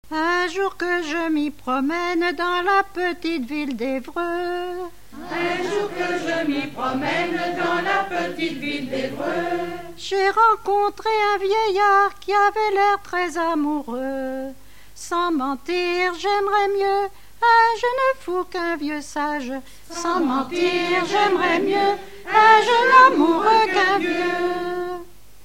Chansons en dansant
Enquête Arexcpo en Vendée-Pays Sud-Vendée
Pièce musicale inédite